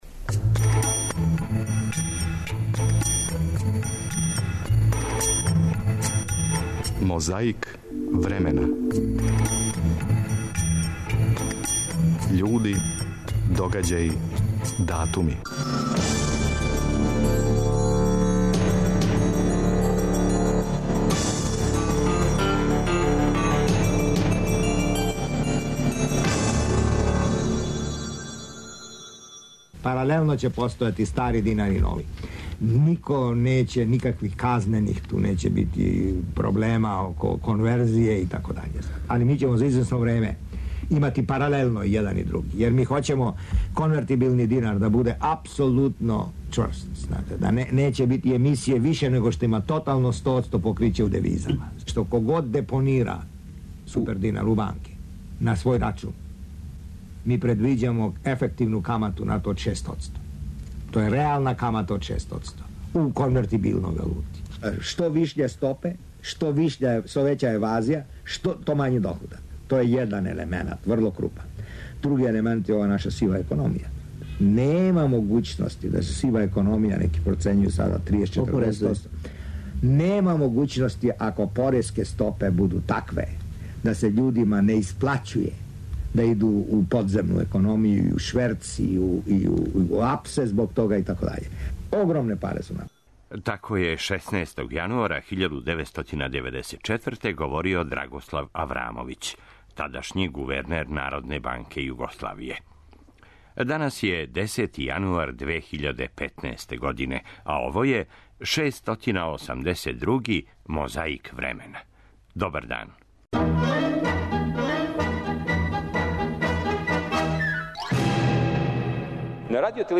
Како је 16. јануара 1994. говорио Драгослав Аврамовић, тадашњи гувернер Народне банке Југославије, слушамо у једној звучној коцкици нашег мозаика.
Подсећа на прошлост (културну, историјску, политичку, спортску и сваку другу) уз помоћ материјала из Тонског архива, Документације и библиотеке Радио Београда.